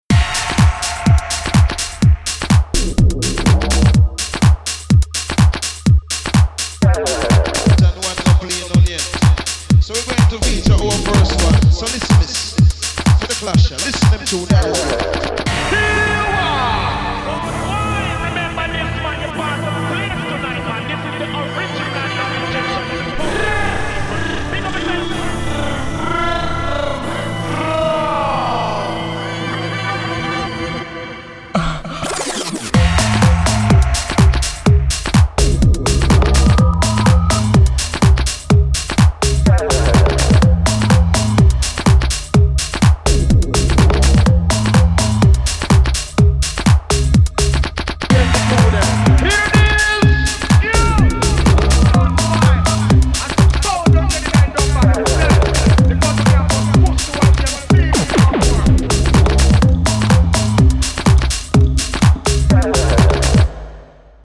Что-то пробило сделать олдскульный гараж а-ля поздние 90-е) Все семплы. Но и никаких лупов.